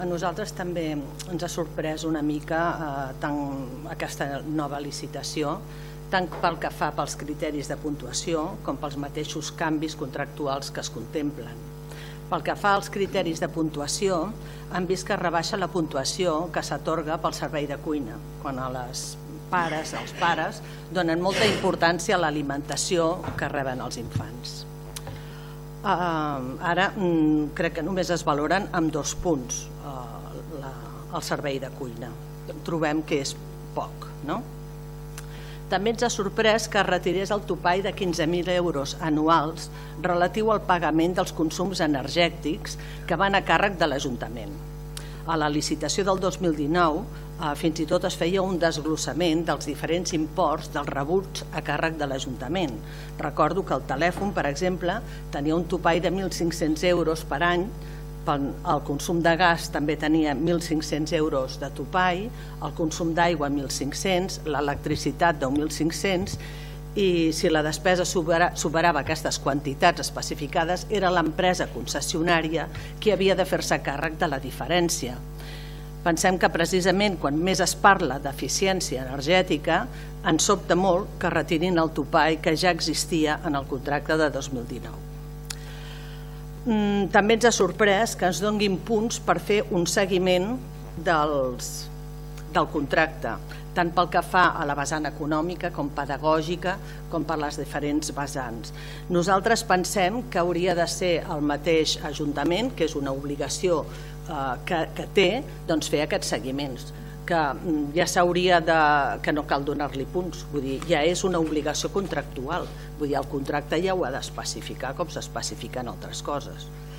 Per la seva part, la portaveu de JuntsxCat, Montse Torres, va enumerar els punts en els quals no estaven d’acord, com la poca puntuació en el servei de cuina o la retirada del topall de 15000 euros anuals del consum energètic: